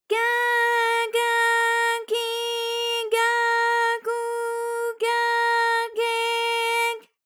ALYS-DB-001-JPN - First Japanese UTAU vocal library of ALYS.
ga_ga_gi_ga_gu_ga_ge_g.wav